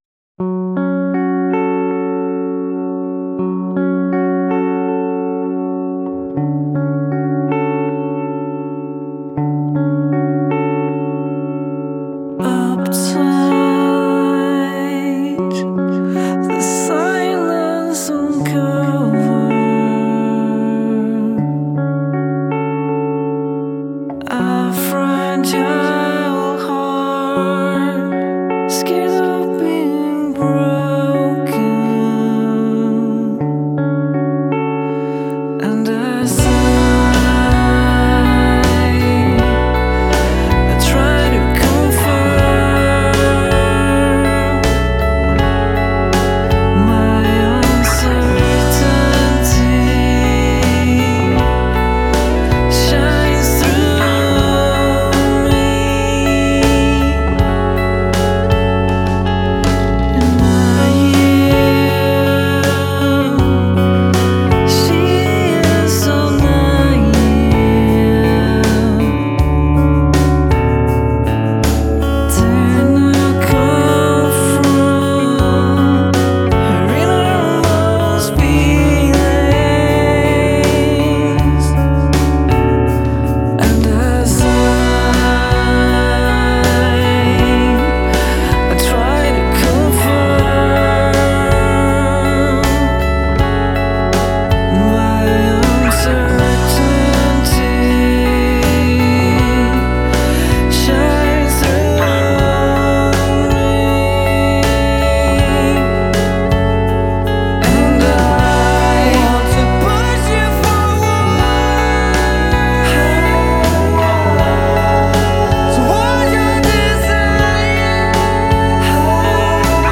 轻缓的 却有一种莫名的感动 其实有时候不需要太用力 如果你想攻占一个人的心的时候